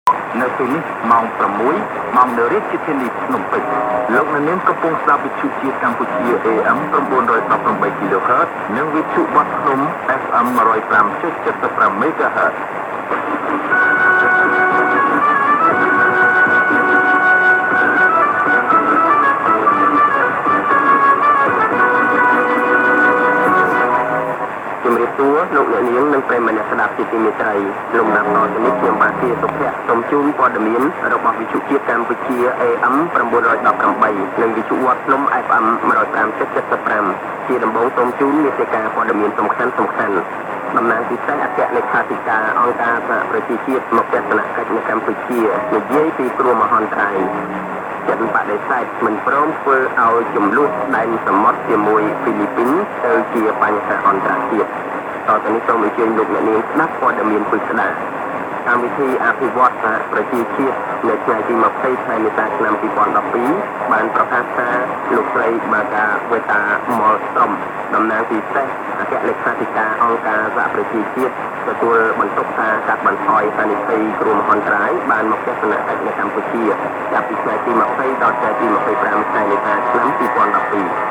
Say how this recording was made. National Radio of Kampuchea @ 918 kHz (MW) heard at 23.00 UTC in West Malaysia. Strong reception up to 00.00 UTC; signal was faintly heard up to 05.00 UTC.